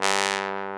Index of /90_sSampleCDs/AKAI S-Series CD-ROM Sound Library VOL-2/1095 TROMBON